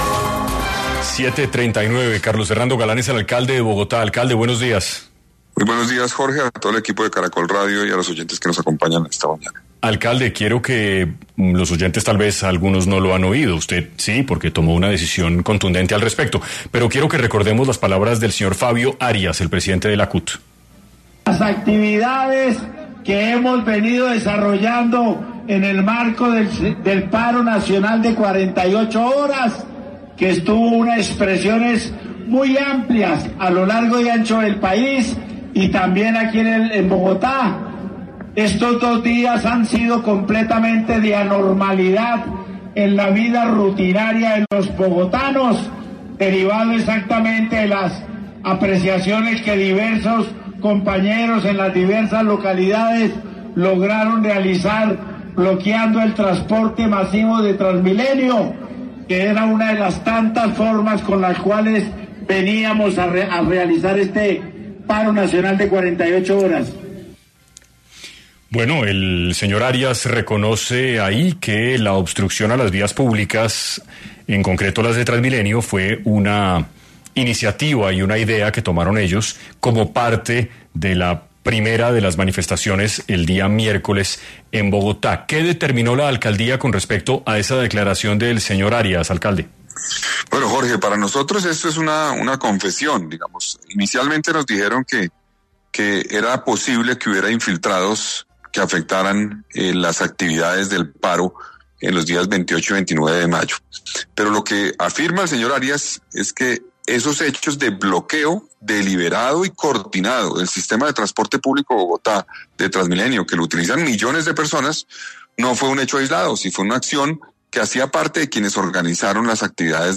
El alcalde de Bogotá estuvo en 6AM de Caracol Radio hablando sobre la denuncia que interpondrá al presidente de la CUT por las movilizaciones del paro nacional.